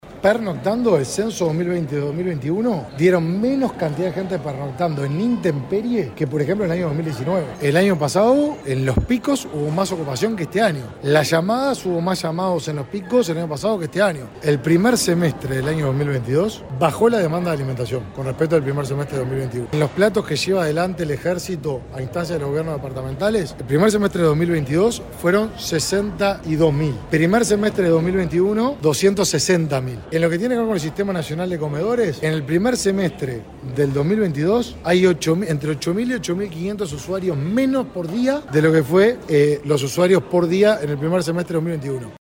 Consultado en rueda de prensa sobre las críticas de falta de insumos de la Coordinadora de Ollas Populares, “datos oficiales nos dan que hay una baja en la demanda de alimentación”, dijo el ministro de Desarrollo Social.